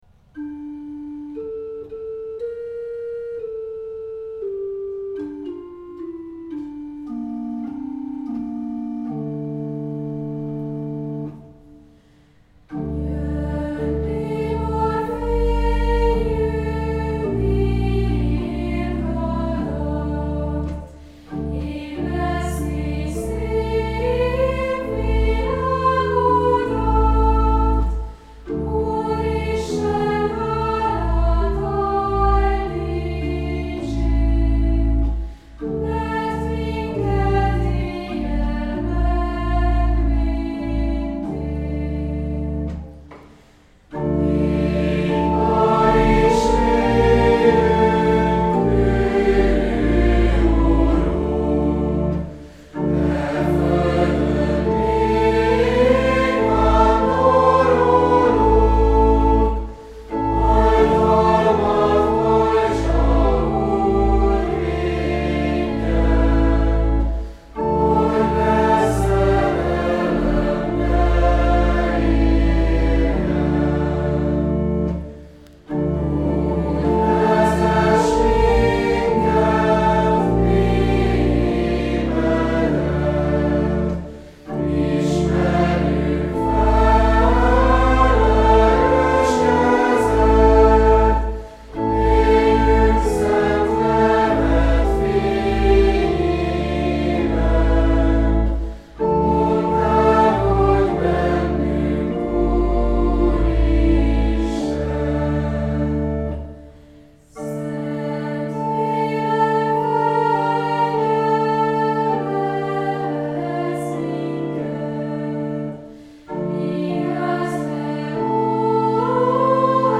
A dór hangsorú dallam kezdetének fellépő kvint hangköze a napfelkeltét szimbolizálhatja. A négysoros strófa gyönyörű, fokozatos ívet jár be: az első két sor felfelé törekszik, a második két sor a megnyugvás felé közelít.